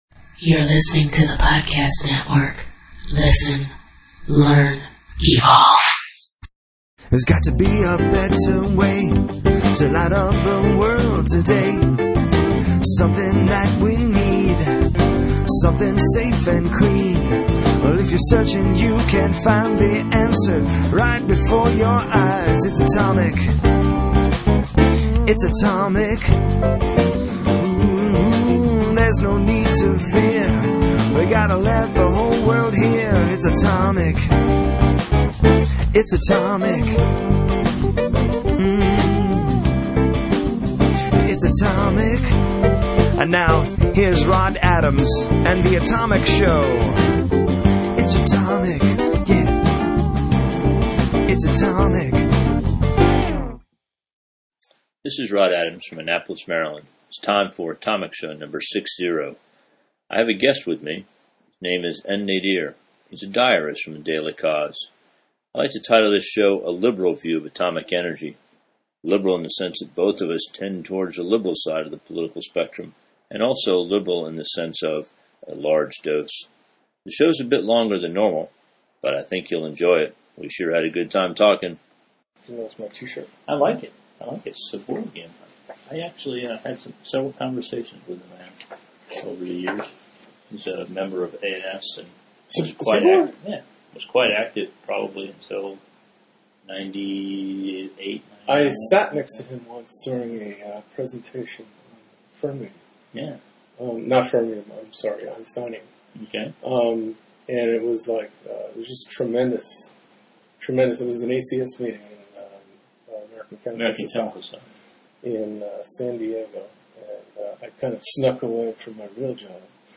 We had never before met, but talked non-stop for more than an hour, finishing the conversation with an agreement to meet again soon.